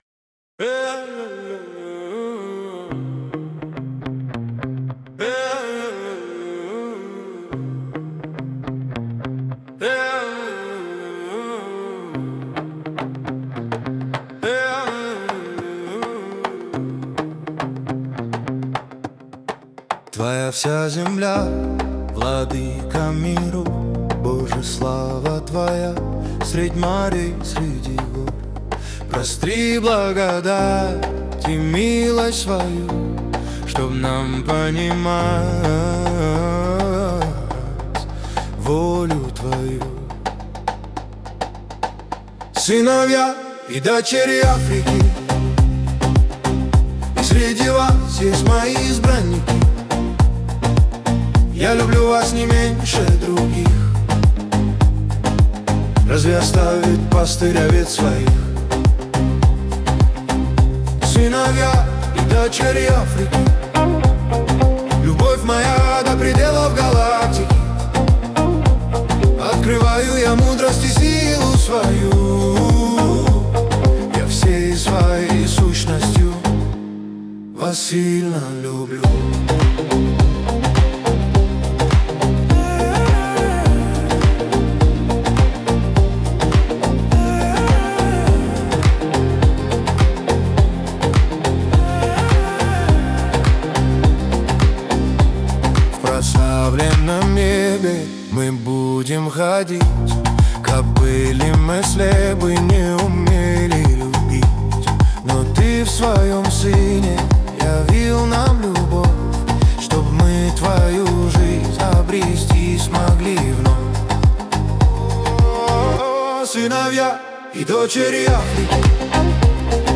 15 просмотров 45 прослушиваний 3 скачивания BPM: 104